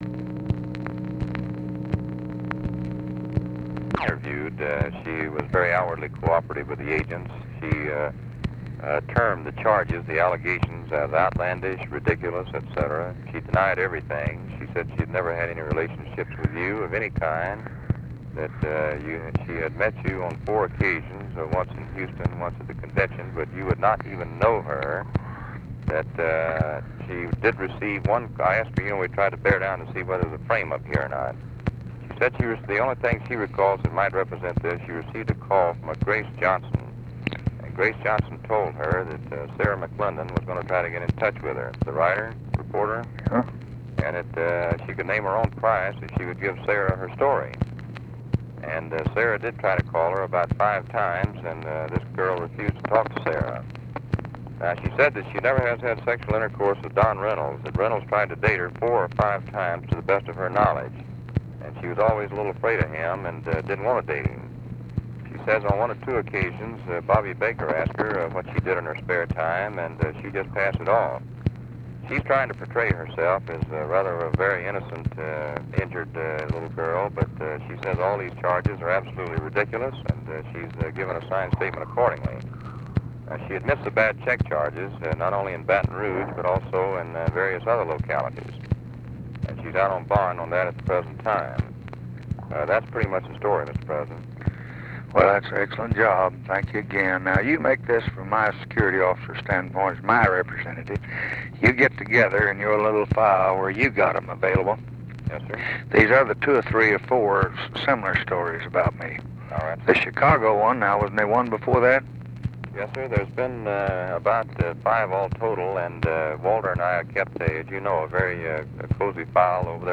Conversation with CARTHA DELOACH, December 3, 1964
Secret White House Tapes